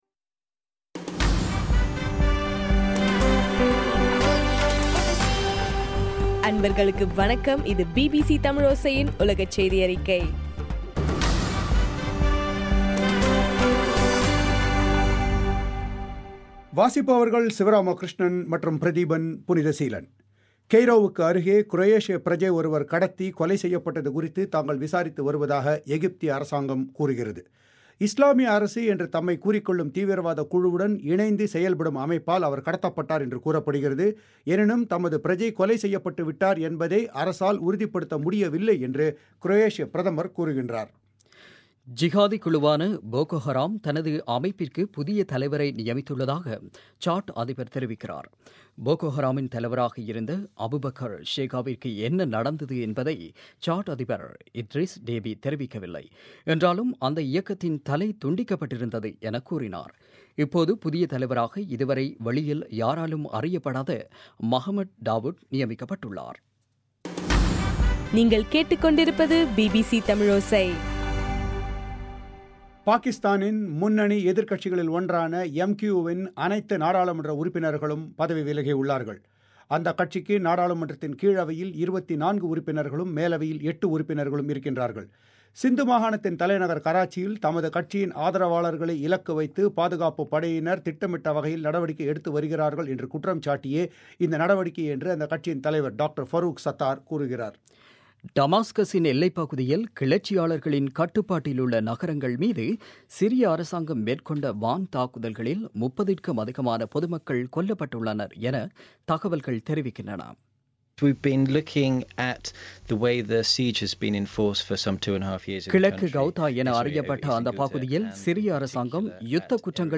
ஆகஸ்ட் 12, 2015 பிபிசி தமிழோசையின் உலகச் செய்திகள்